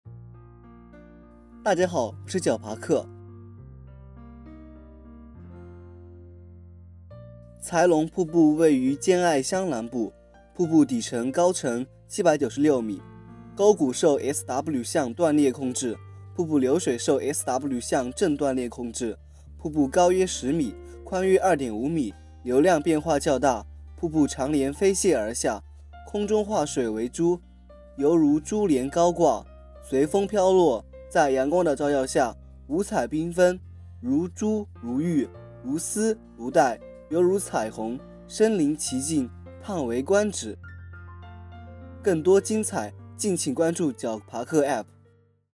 才龙瀑布----- 手机用户 解说词: 才龙瀑布位于兼爱乡南部，瀑布底部高程796m。